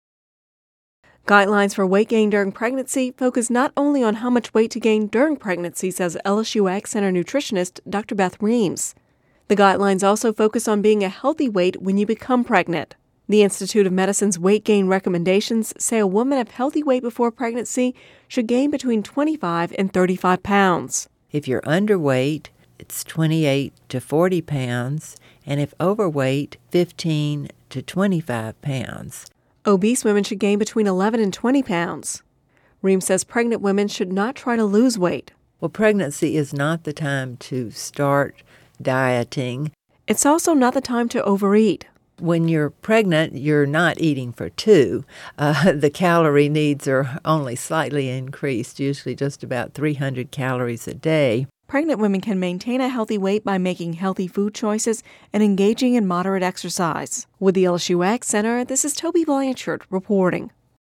(Radio News 09/20/10) Guidelines for weight gain during pregnancy don't just focus on how much weight to gain during pregnancy